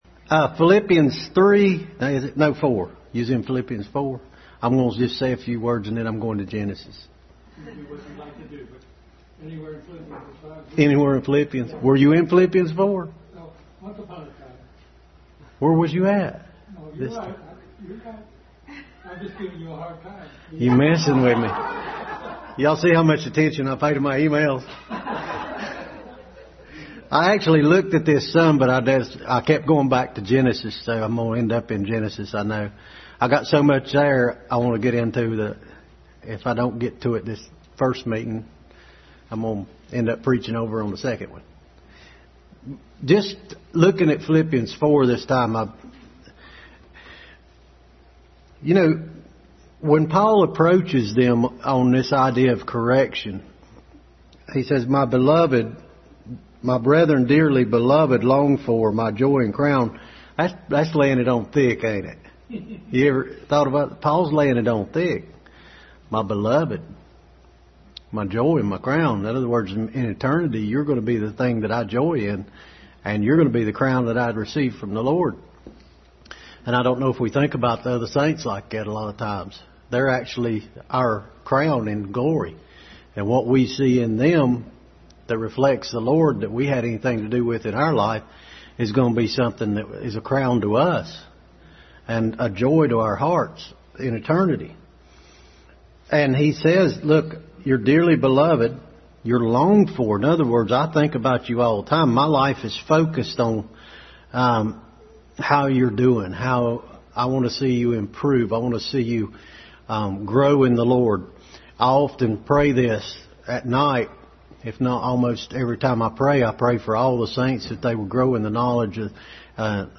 Adult Sunday School continued study in Philippians.